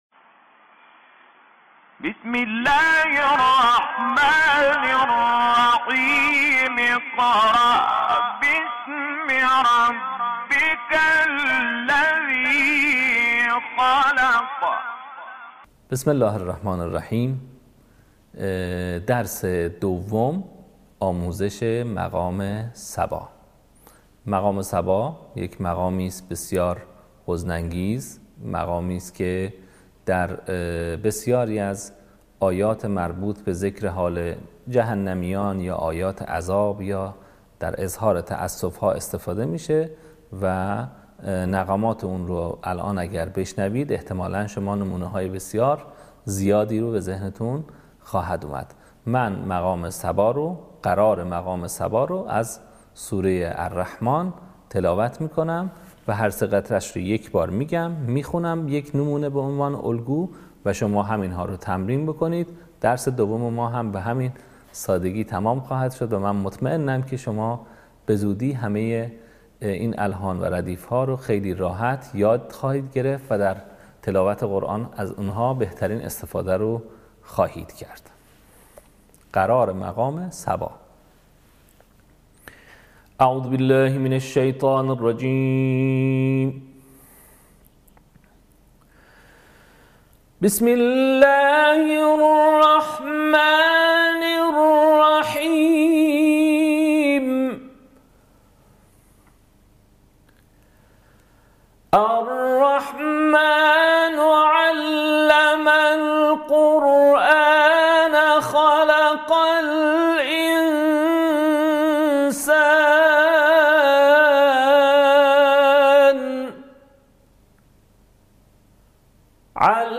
صوت | آموزش مقام صبا